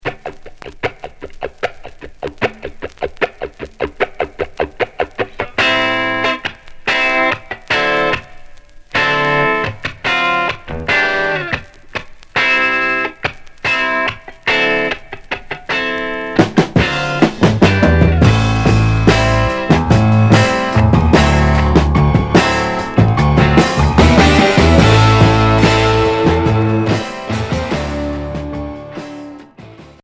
Groove rock Unique 45t retour à l'accueil